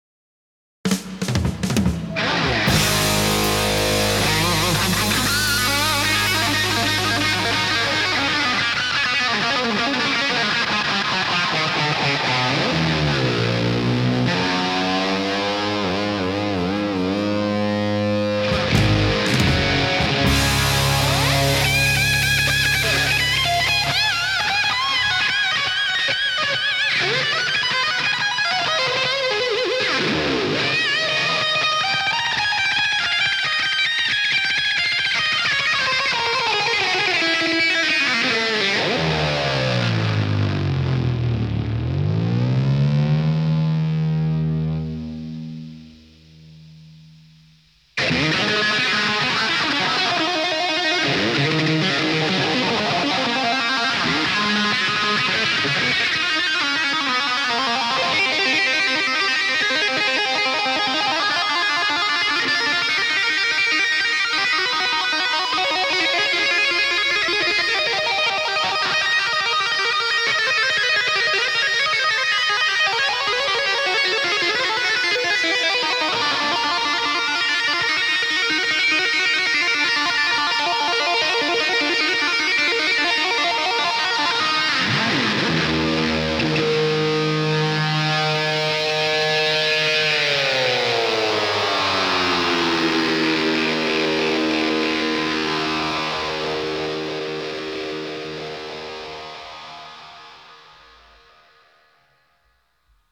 Hard Rock
سولو